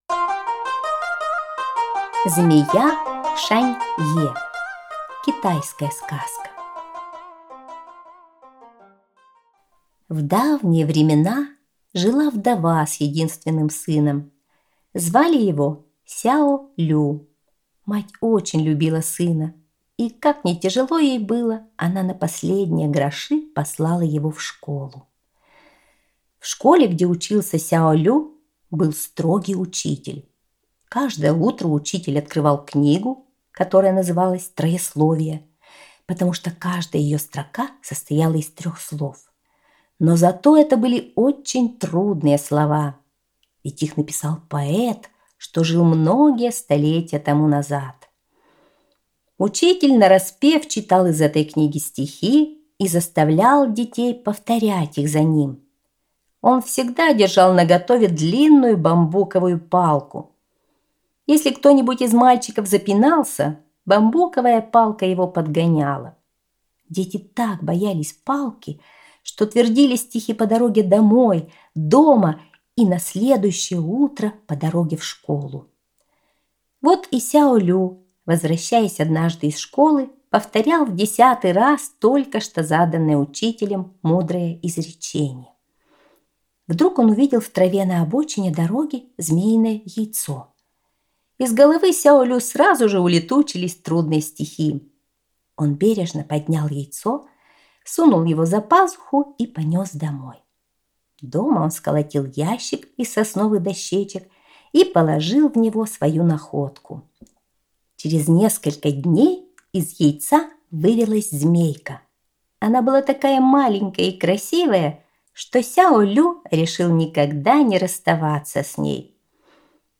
Змея Шань-е - китайская аудиосказка - слушать онлайн